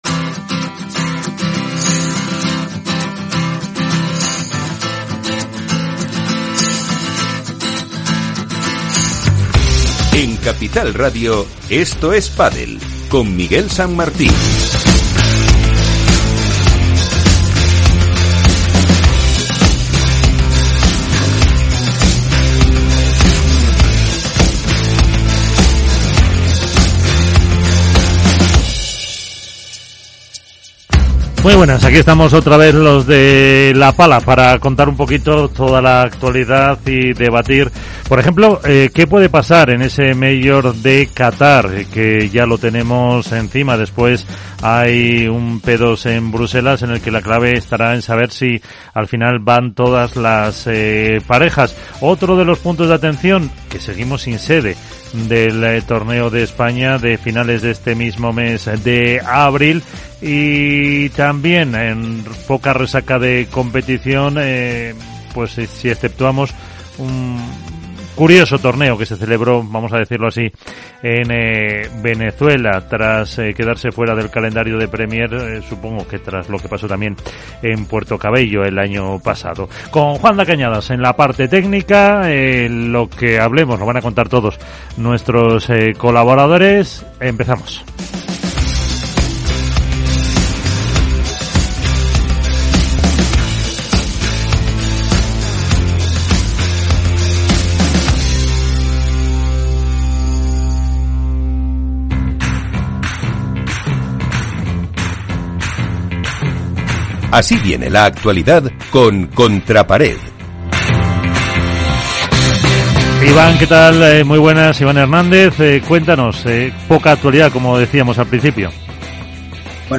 El programa de radio para el aficionado.
Dos horas repletas de historias, curiosidades y de entrevistas con los personajes más relevantes. Un completo seguimiento a todo lo que sucede en el pádel profesional.